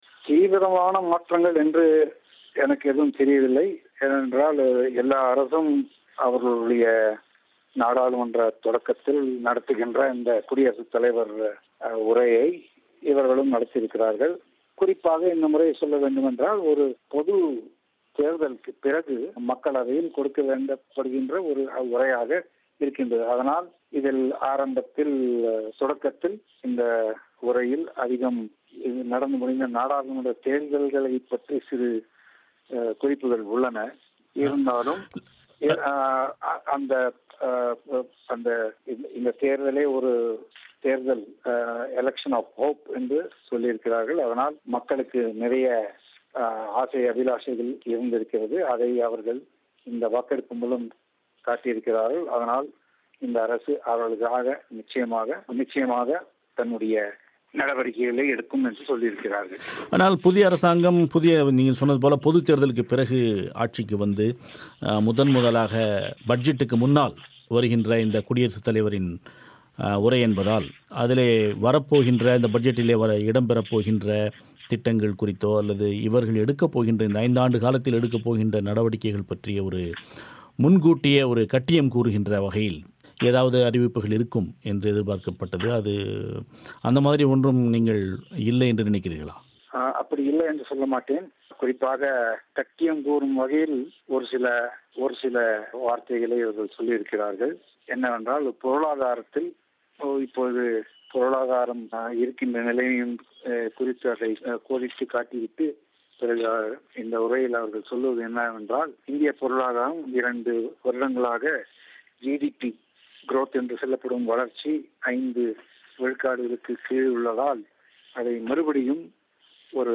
தமிழோசைக்கு வழங்கிய செவ்வி.